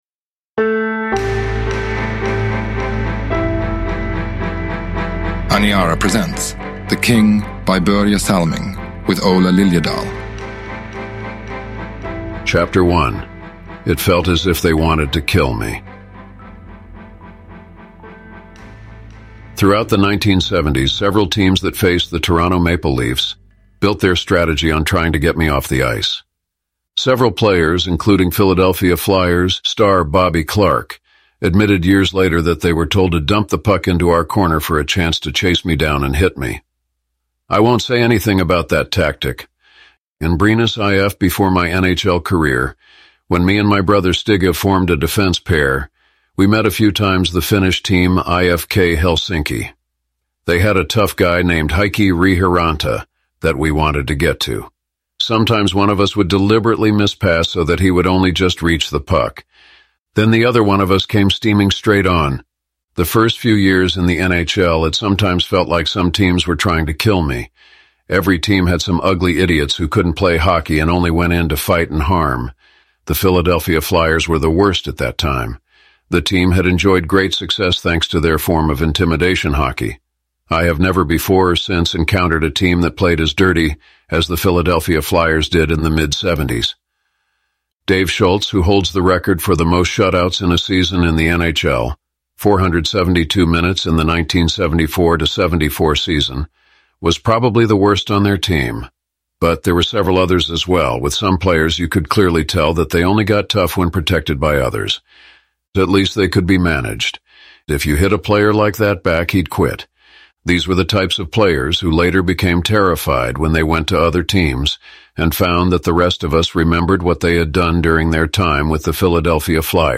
Uppläsare: Aniara AI Oswald
Ljudbok